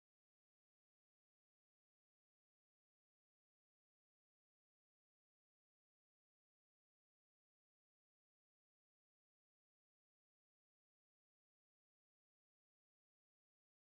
silence-long.m4a